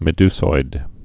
(mĭ-dsoid, -zoid, -dy-)